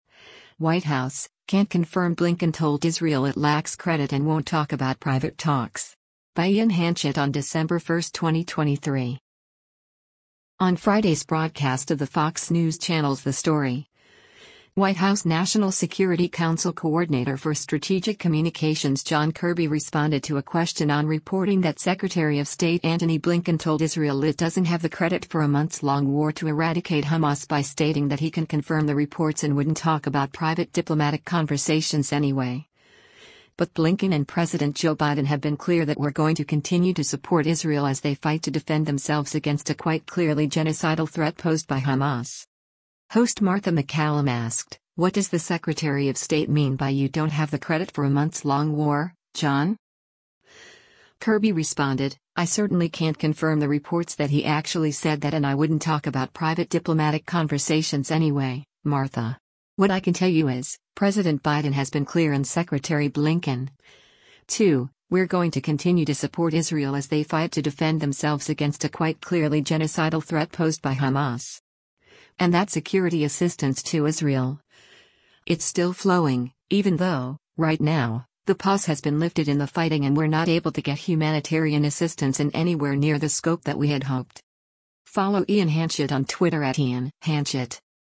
On Friday’s broadcast of the Fox News Channel’s “The Story,” White House National Security Council Coordinator for Strategic Communications John Kirby responded to a question on reporting that Secretary of State Antony Blinken told Israel it doesn’t have the “credit” for a months-long war to eradicate Hamas by stating that he can’t confirm the reports and “wouldn’t talk about private diplomatic conversations anyway,” but Blinken and President Joe Biden have been clear that “we’re going to continue to support Israel as they fight to defend themselves against a quite clearly genocidal threat posed by Hamas.”
Host Martha MacCallum asked, “What does the Secretary of State mean by you don’t have the credit for a months-long war, John?”